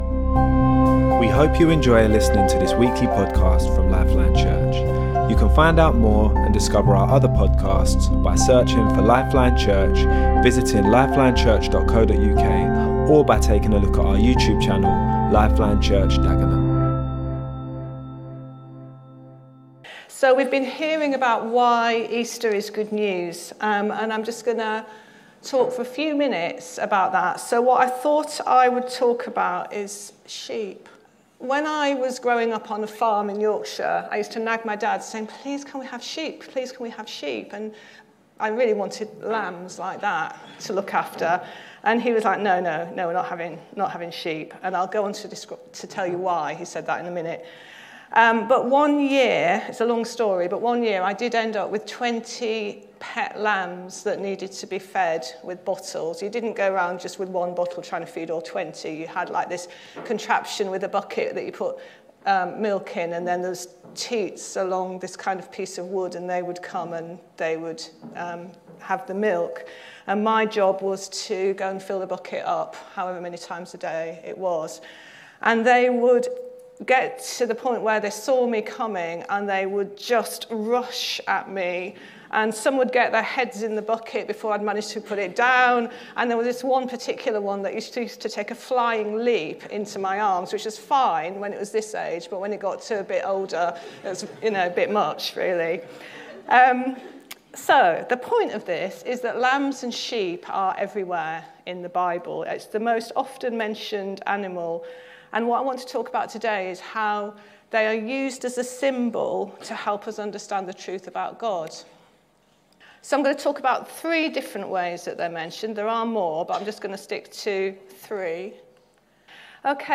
Our Sunday teaching sometimes follows a theme, a book or explores the ‘now’ word of God to us as a community.